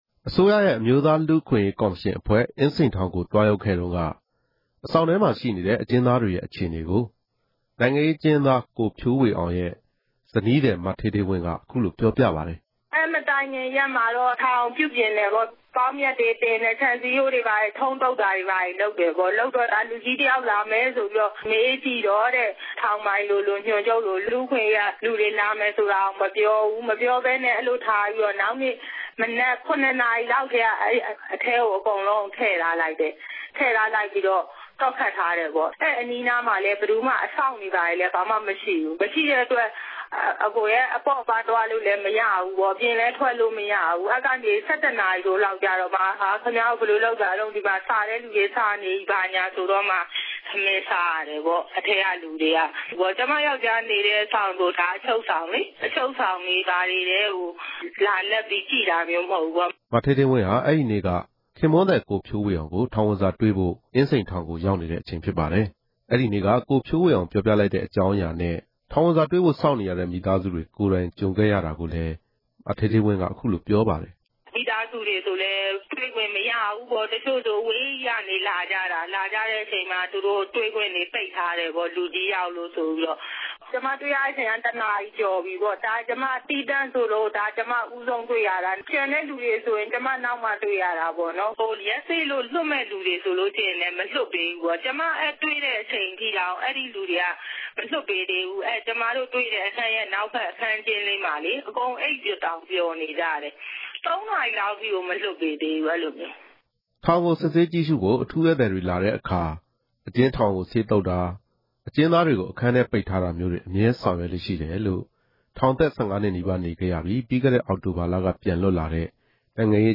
ဆက်သွယ်မေးမြန်းချက်။